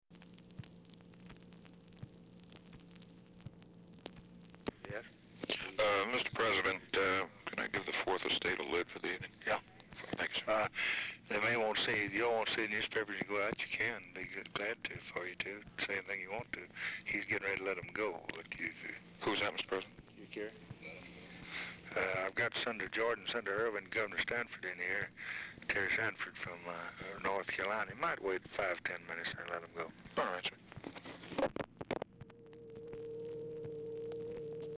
Telephone conversation # 5577, sound recording, LBJ and MAC KILDUFF, 9/14/1964, 6:30PM | Discover LBJ
OFFICE CONVERSATION DURING CALL
Dictation belt
Oval Office or unknown location